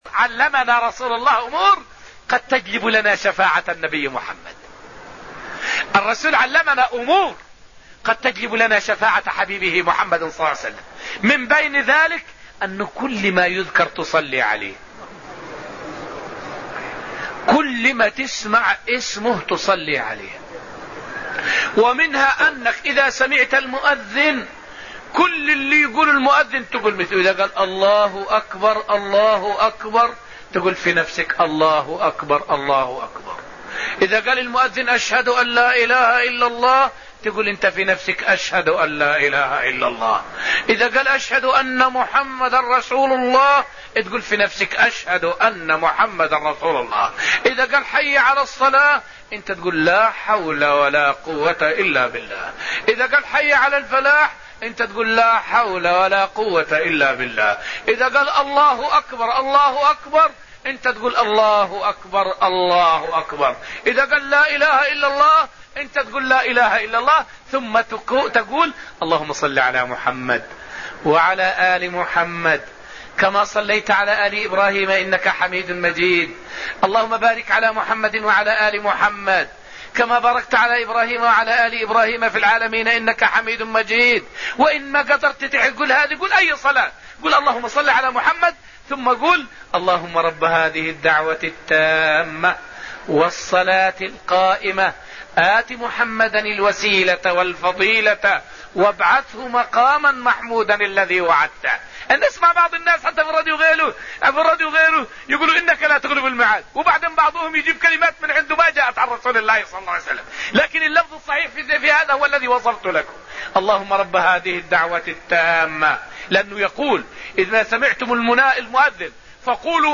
فائدة من الدرس التاسع من دروس تفسير سورة النجم والتي ألقيت في المسجد النبوي الشريف حول أمور تجلب لنا شفاعة النبي صلى الله عليه وسلم.